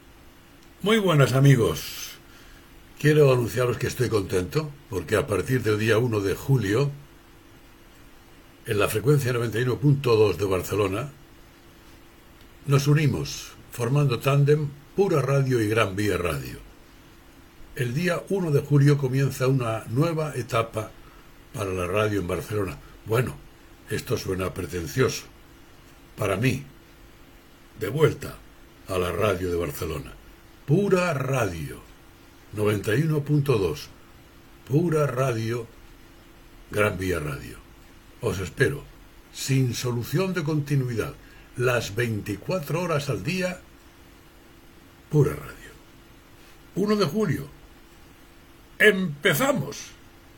Anunci